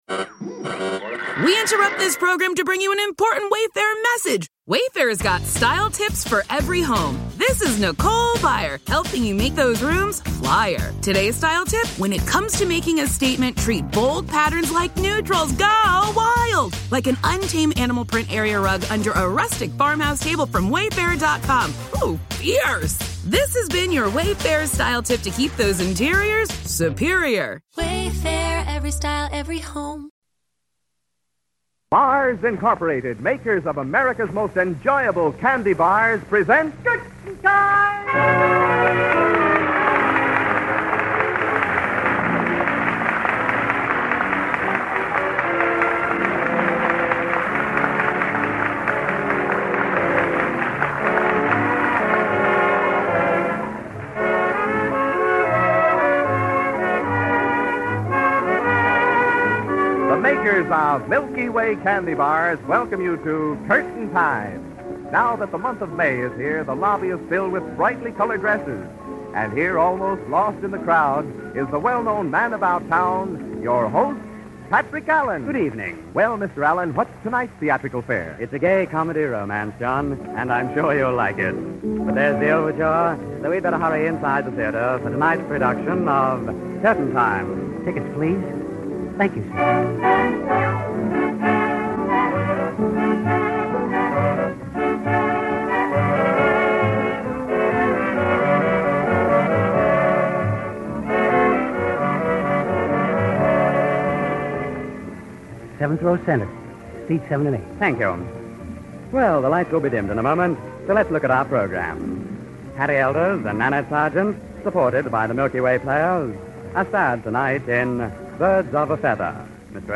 Curtain Time was a popular American radio anthology program that aired during the Golden Age of Radio. It was known for its romantic dramas and its unique presentation style that aimed to recreate the atmosphere of attending a live theater performance.Broadcast History: 1938-1939: The show first aired on the Mutual Broadcasting System from Chicago. 1945-1950: It had a much more successful run on ABC and NBC, gaining a wider audience and greater popularity.